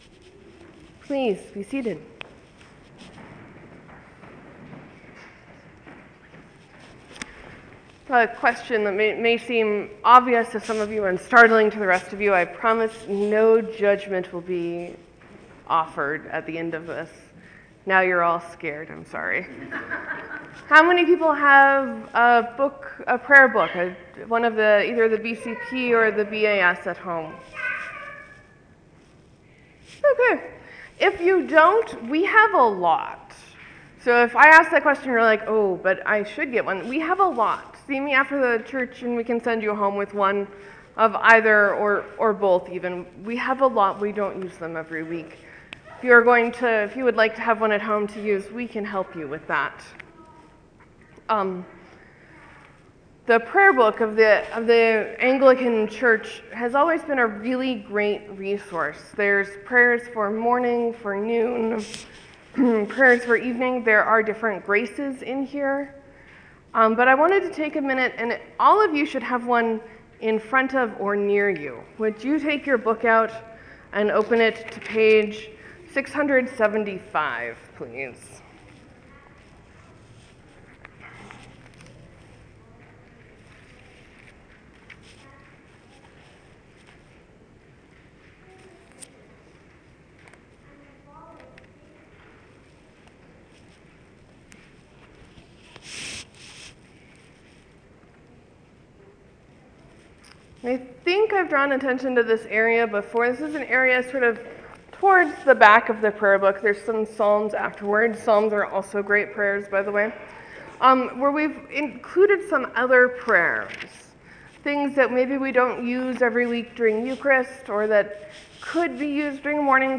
The Sermon: Jesus gets up in the middle of his home synagogue and does a new thing and an old thing.